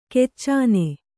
♪ keccāne